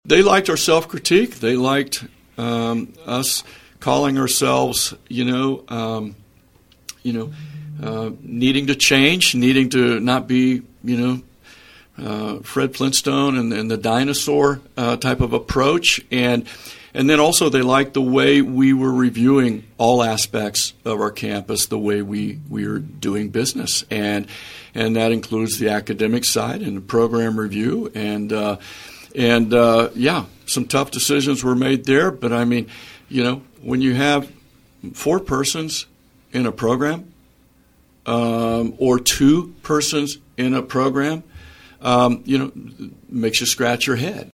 Emporia State University President Ken Hush says a transformation is fully underway on campus, and he offered his perspective on KVOE’s Morning Show on Thursday.